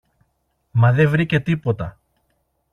male_ref.mp3